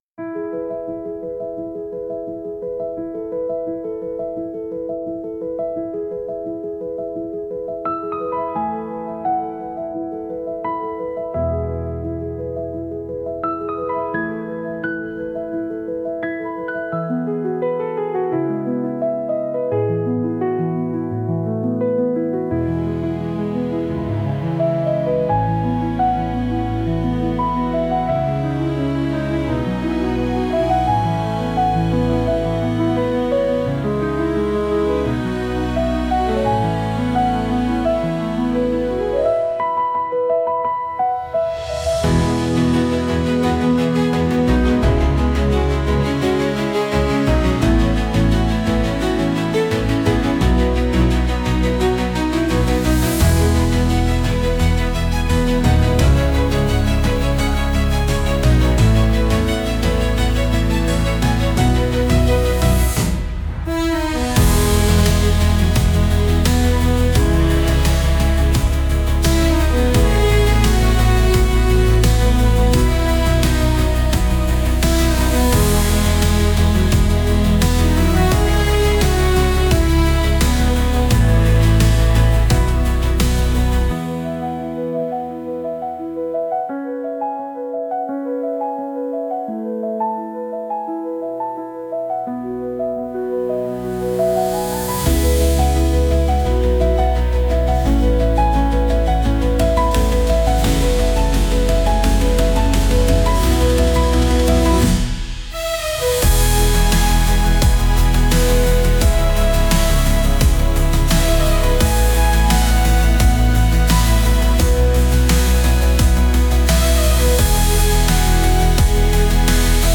社会をより豊かにしていくためのBGM